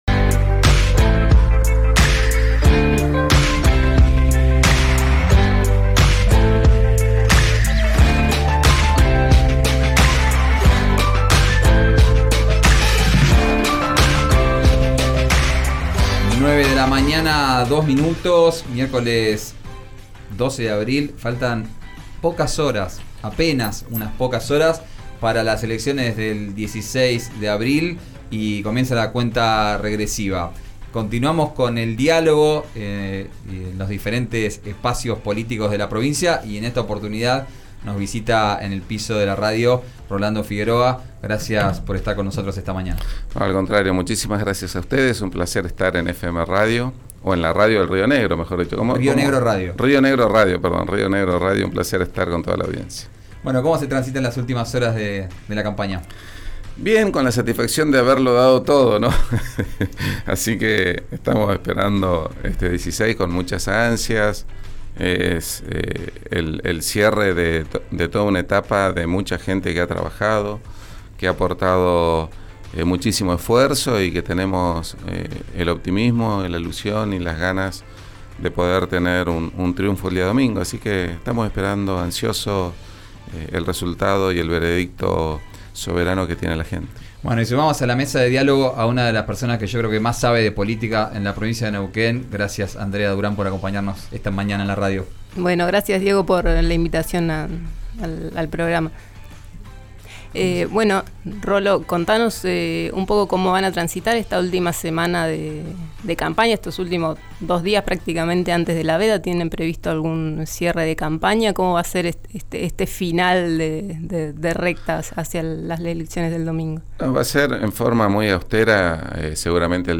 El candidato de Comunidad se encuentra en los estudios de Río Negro Radio, a cuatro día de las elecciones en las que busca romper con un gobierno de más de seis décadas del MPN.